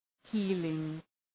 {‘hi:lıŋ}